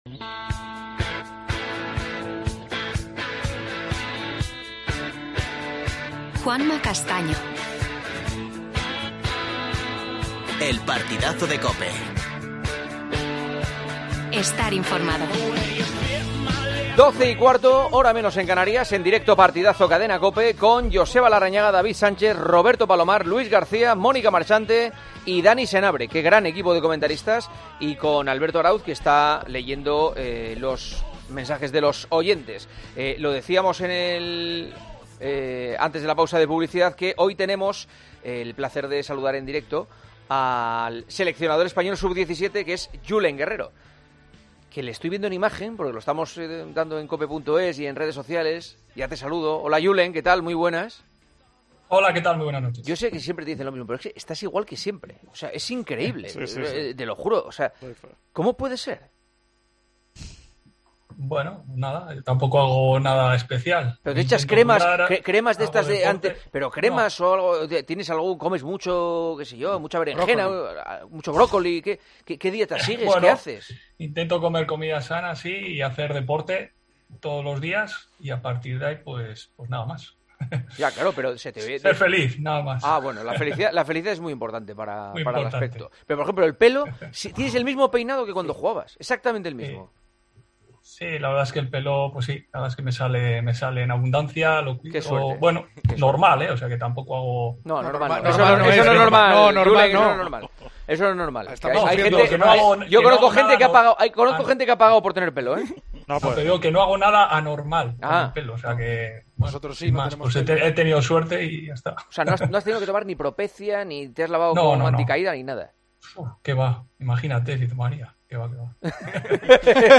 AUDIO: Entrevista a Julen Guerrero, seleccionador español sub 17.